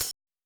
hihat02.wav